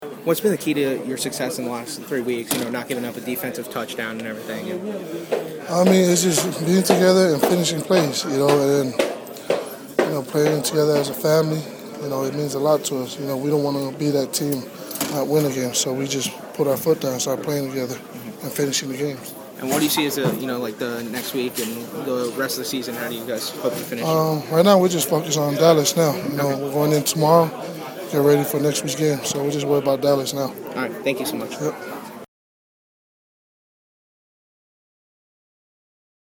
The third person I talked to in the Dolphins’ Locker Room was Paul Soliai.
phins-paul-soliai-locker-room.mp3